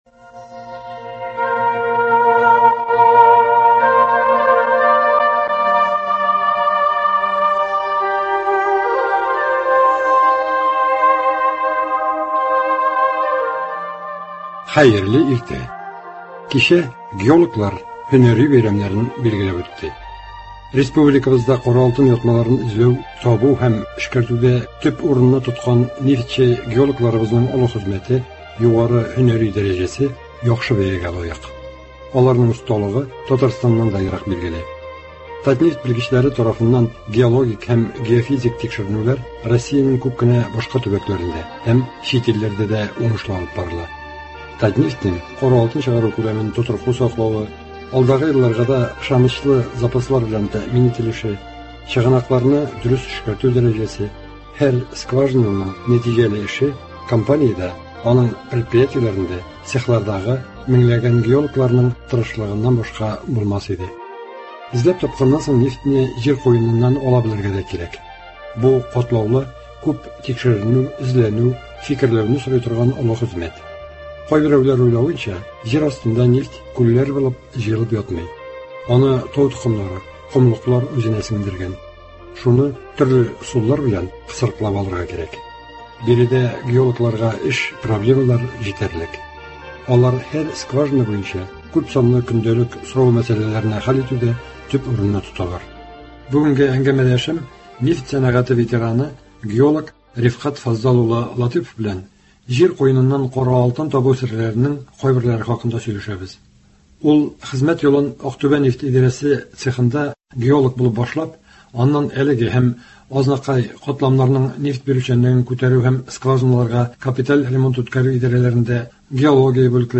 кызыклы әңгәмә.